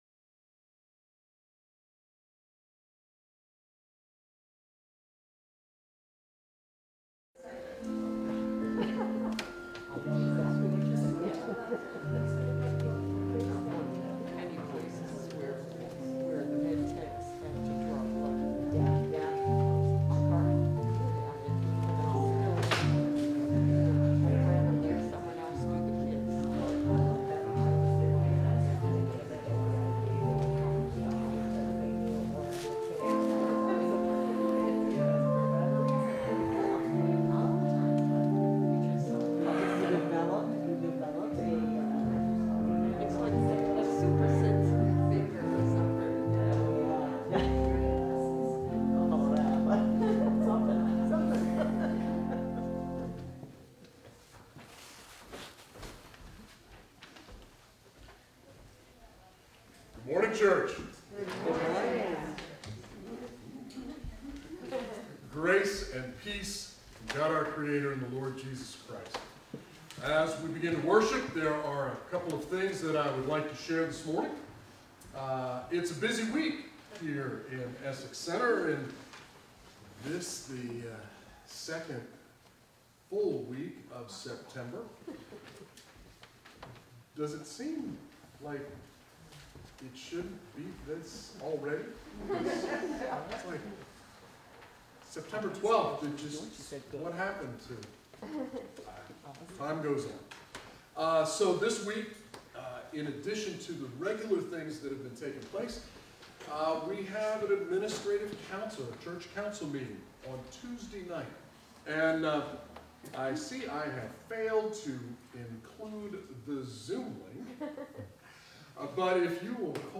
We welcome you to either virtual or in-person worship on Sunday, September 12, 2021 at 10am!
Sermons Am I (are we) a 501.c.3?